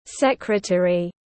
Thư ký tiếng anh gọi là secretary, phiên âm tiếng anh đọc là /ˈsekrəteri/.
Secretary /ˈsekrəteri/